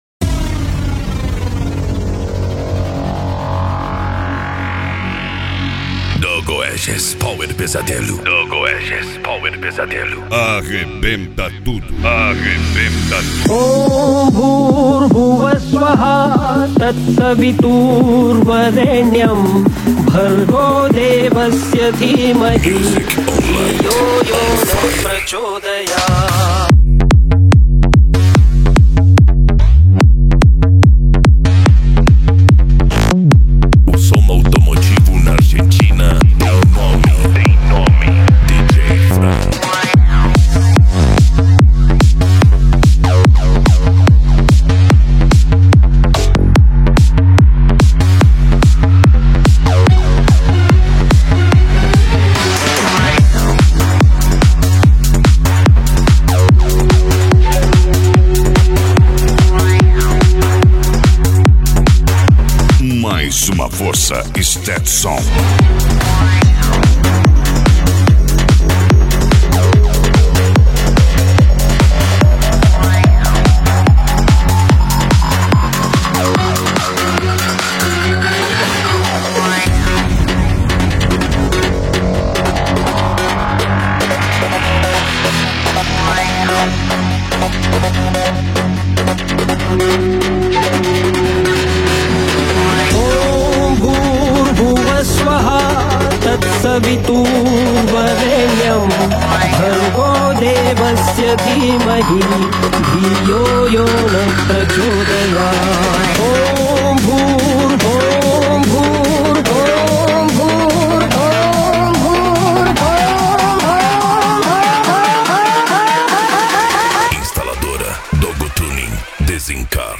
Musica Electronica
Psy Trance
Remix
Techno Music
Trance Music